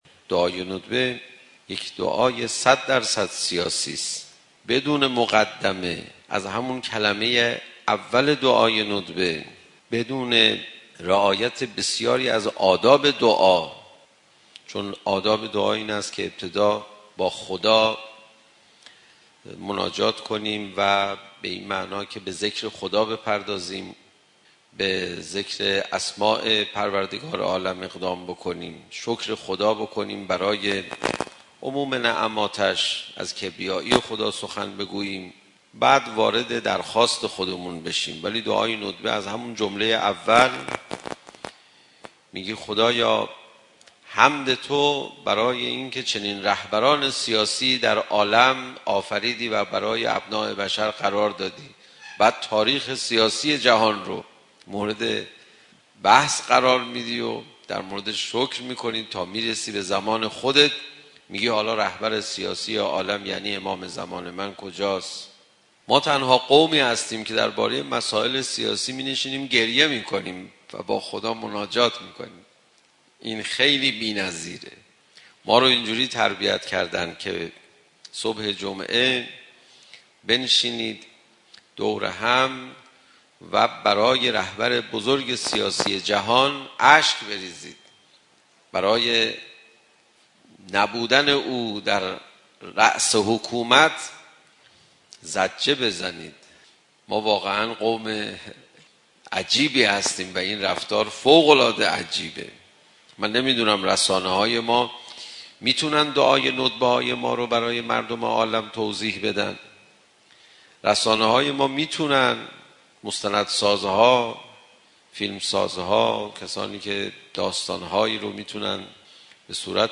صوت سخنان حجت الاسلام پناهیان – ندبه، سیاسی ترین دعا